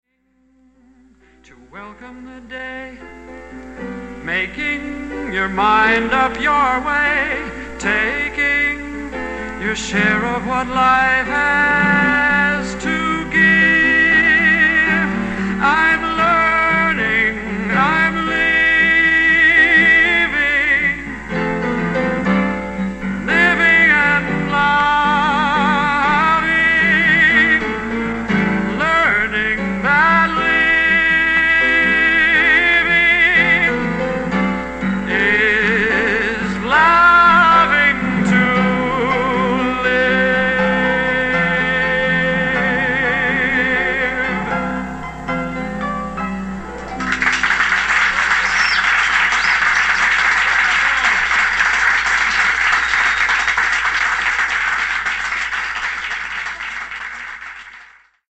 a final ballad